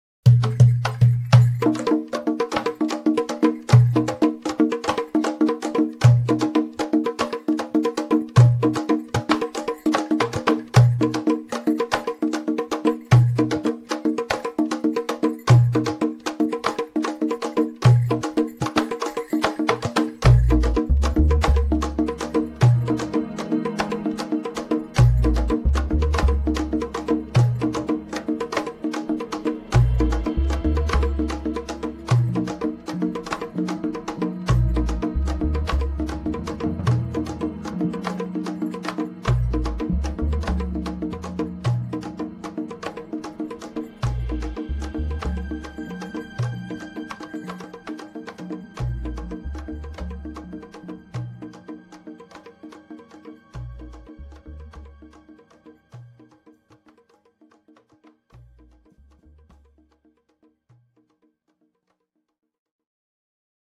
vocals, alto saxophone, bata drum, congas
keyboards
bass
drums